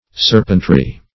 Search Result for " serpentry" : The Collaborative International Dictionary of English v.0.48: Serpentry \Ser"pent*ry\, n. 1.